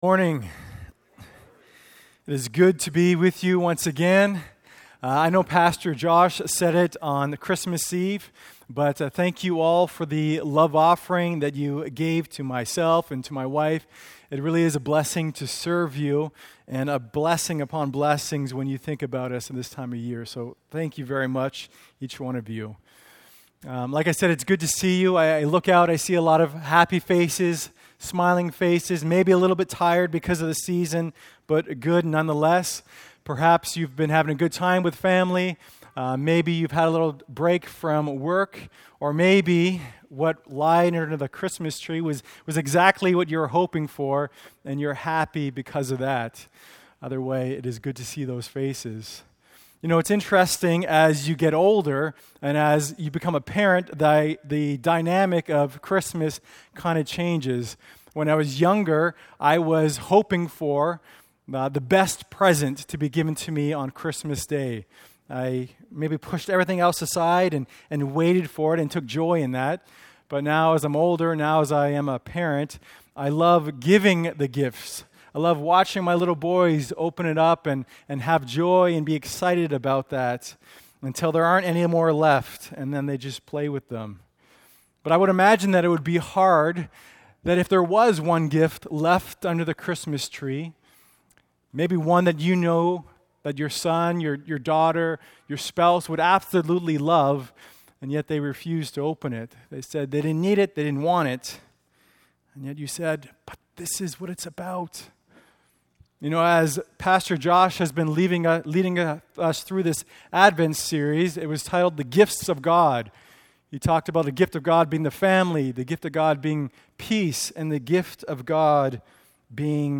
Sermon Outline:1. Relying in the Flesh Brings Failure2. Receiving Christ Brings Eternal Life